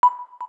KEYTONE1_7.wav